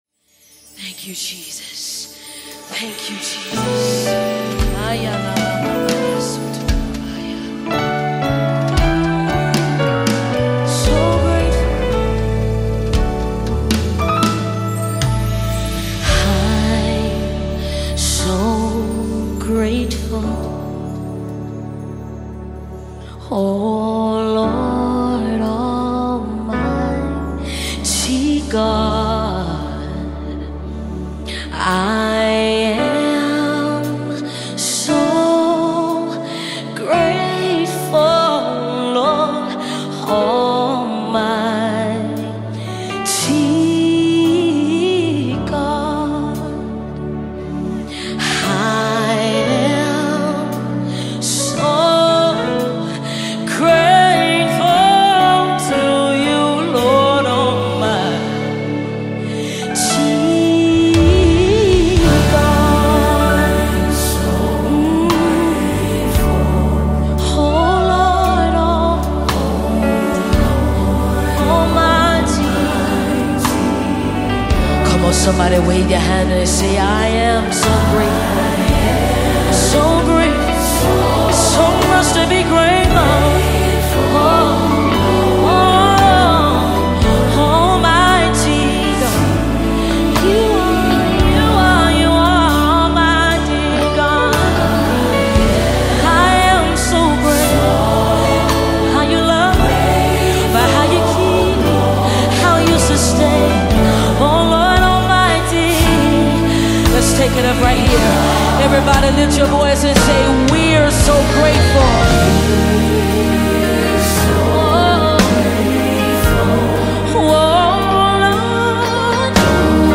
Home » Gospel
It keeps a steady rhythm that feels just right.